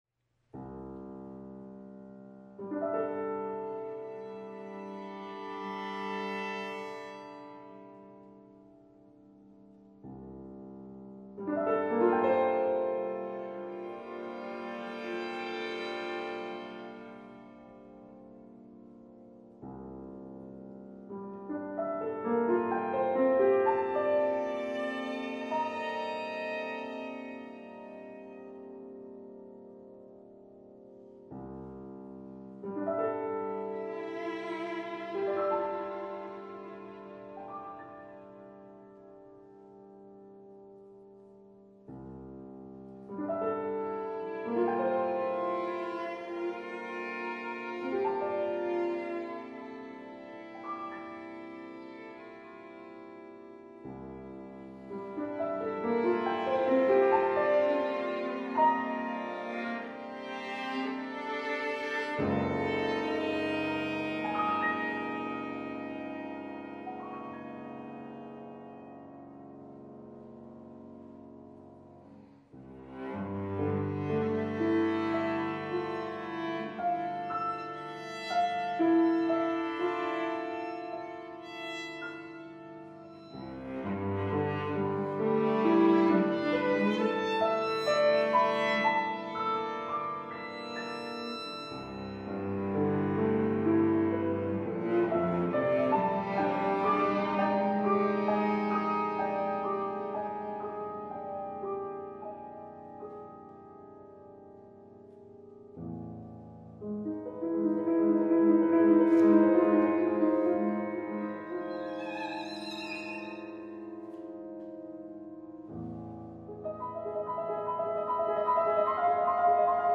violin, violoncello, piano